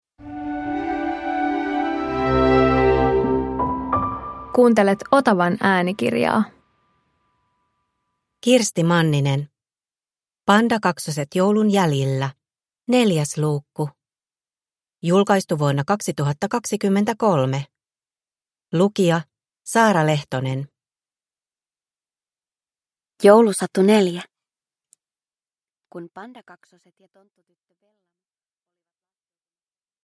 Pandakaksoset joulun jäljillä 4 – Ljudbok